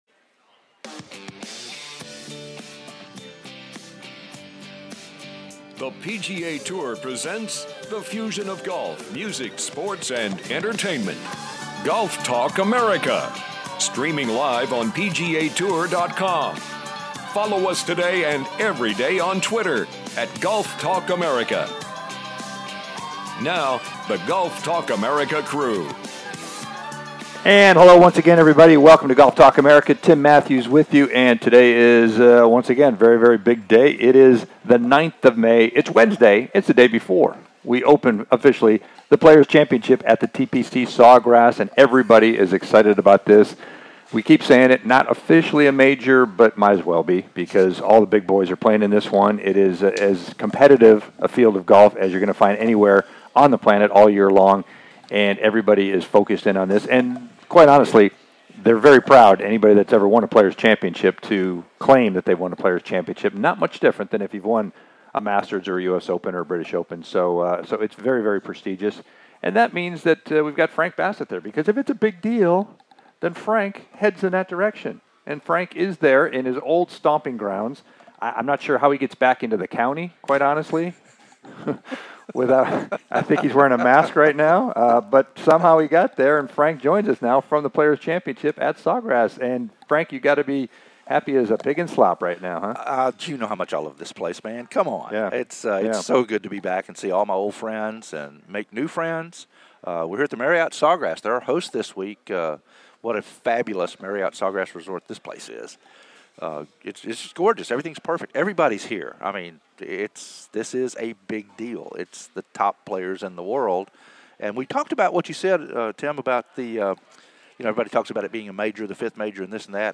2 Time Players Champion Steve Elkington Joins "The Crew" LIVE From Sawgrass